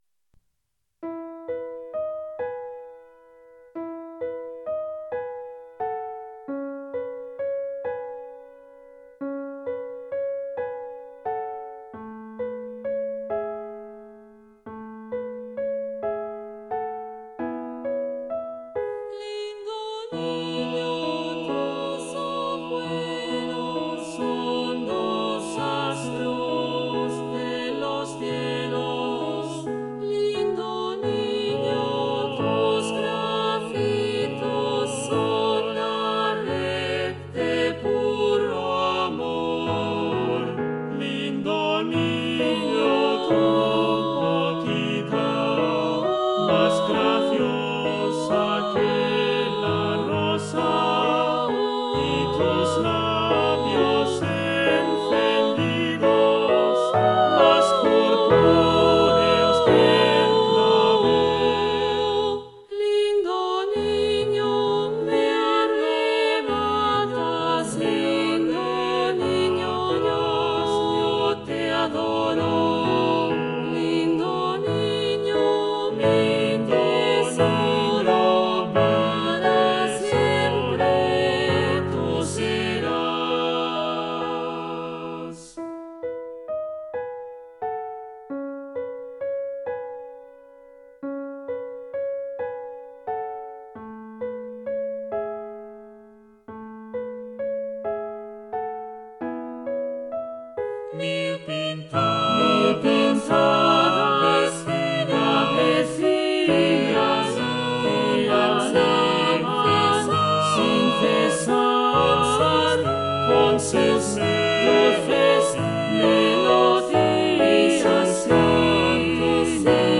SATB + Piano 3’30”
SATB, Piano